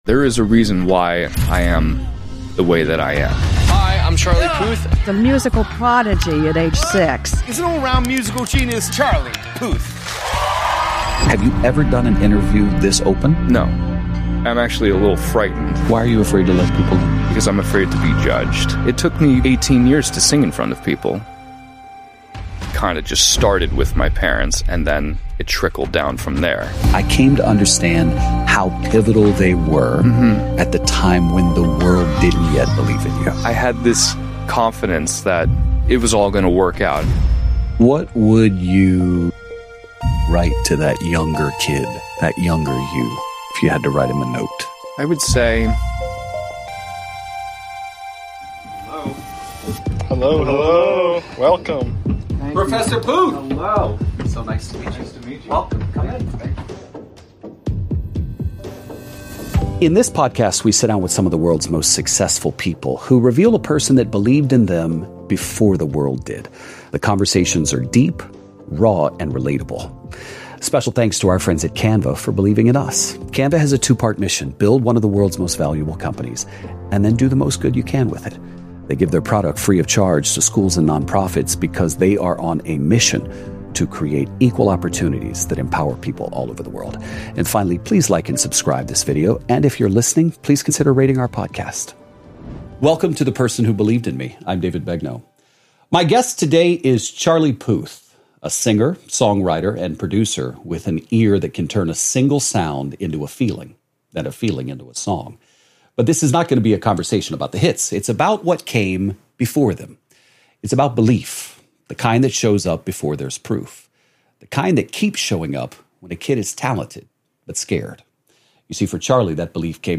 In this deeply personal and unguarded conversation, the Grammy-nominated singer, songwriter, and producer sits down with David Begnaud to share the people who believed in him when he was too afraid to believe in himself.